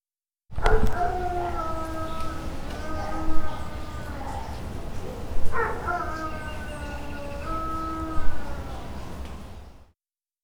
Proposição sonora: coleção (em processo) de uivos de cachorros (alternando-se 17 minutos de sons de uivos com 17 minutos de silêncio)
3. uivo longínquo
3_uivo_longinquo.wav